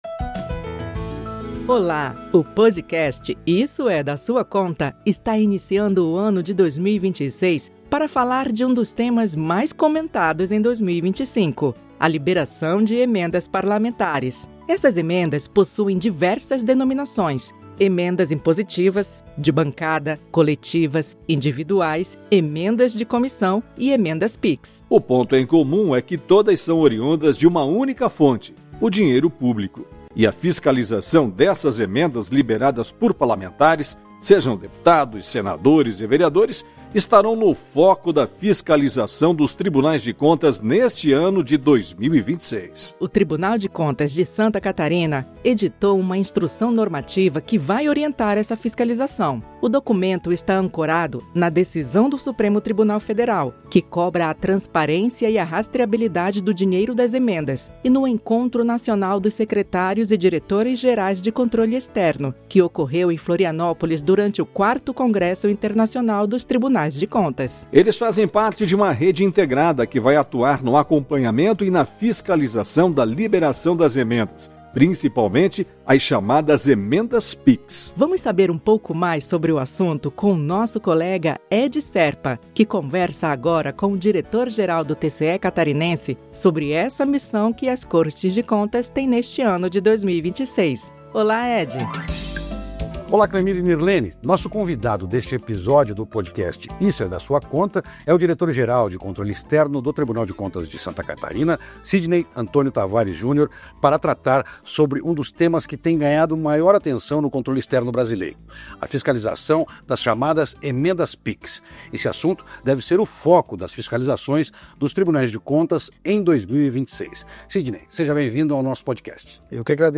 ABRE TRILHA
RODA ENTREVISTA